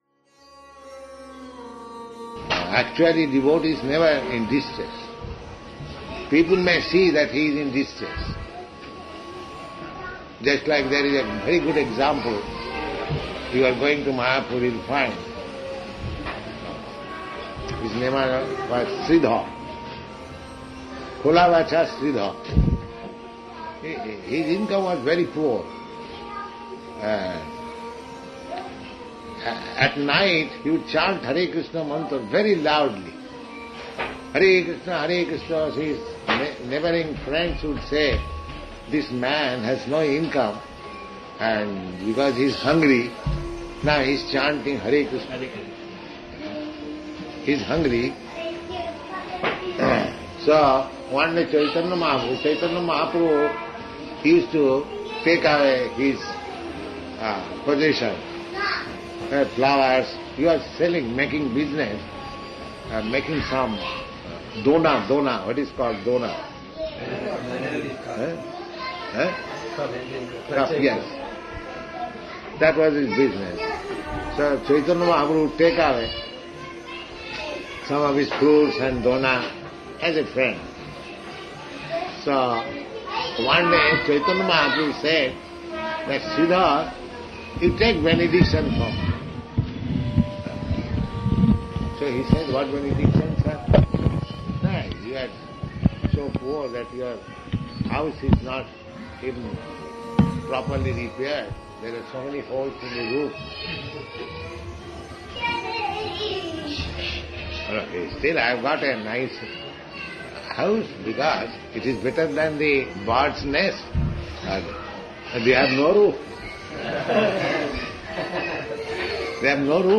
(720223 – Lecture SB 01.02.06 – Calcutta)